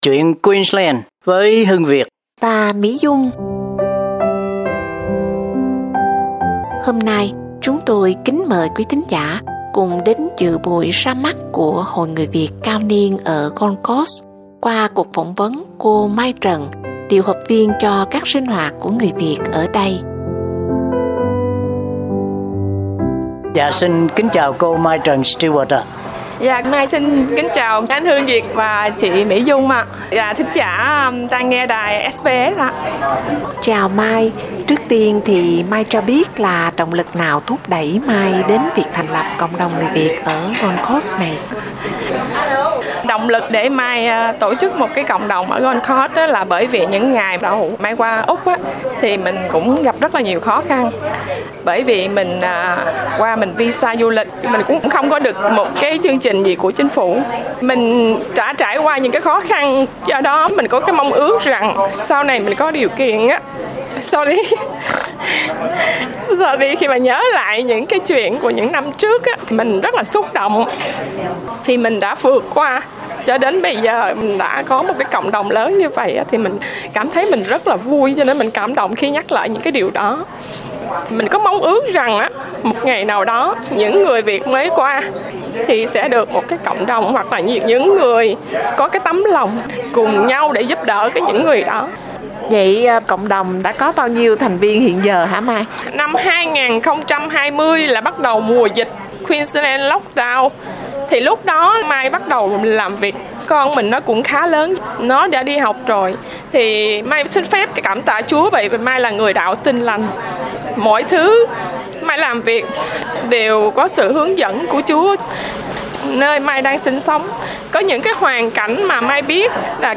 cuộc phỏng vấn